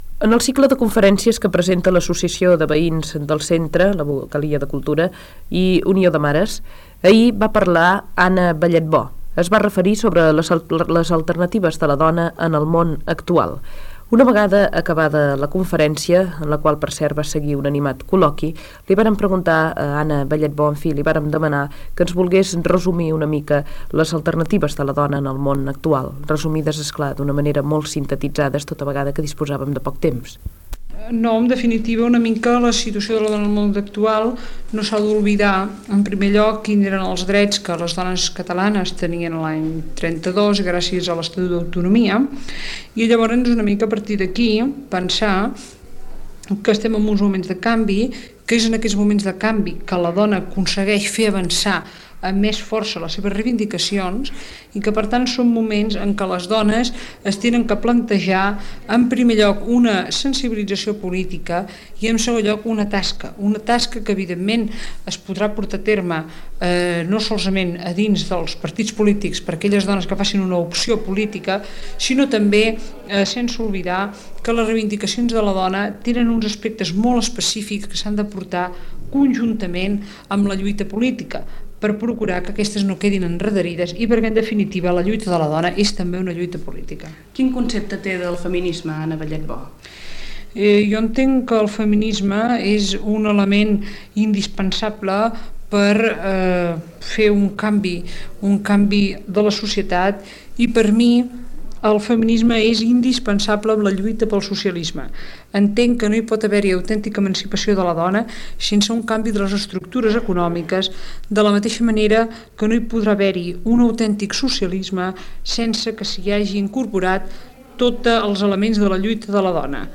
Entrevista a Anna Balletbó, després d'haver fet una conferència a Sabadell, sobre les alternatives de les dones al món d'aquell moment
Informatiu